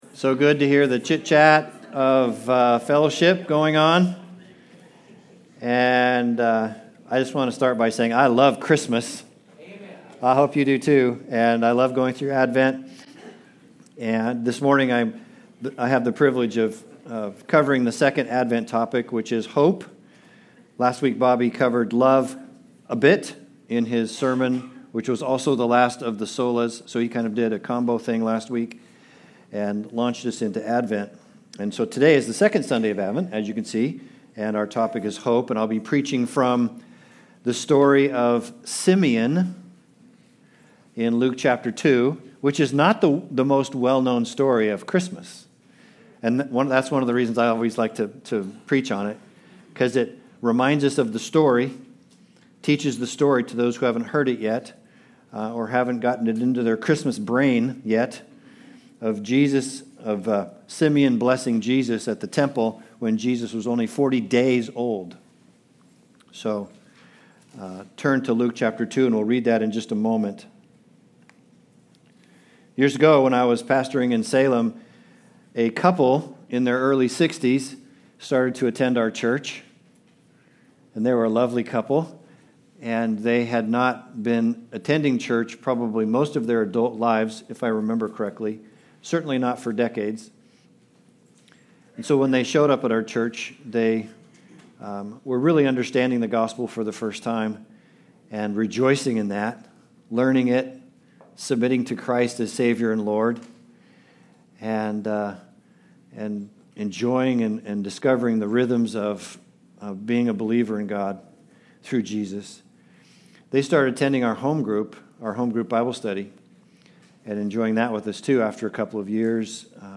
Advent 2025 Passage: Luke 2:25-35 Service Type: Sunday Service Related « Soli Deo Gloria